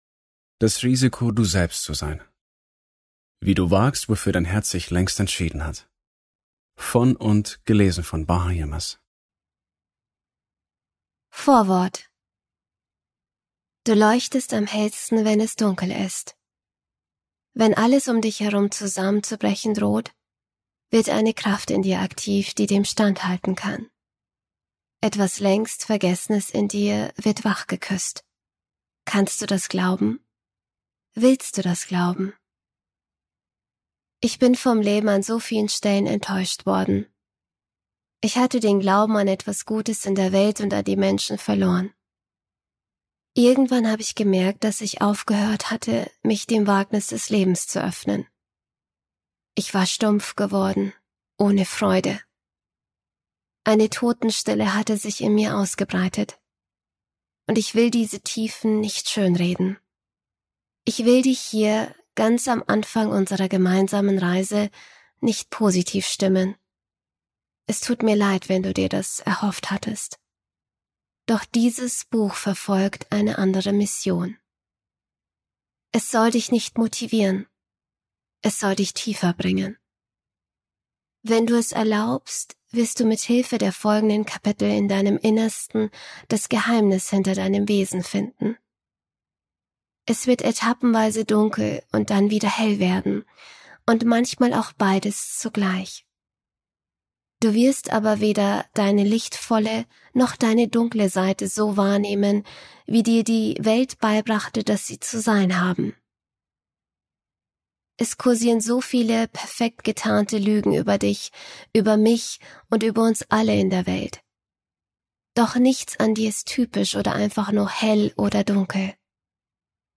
Ungekürzte Autorinnenlesung (1 mp3-CD)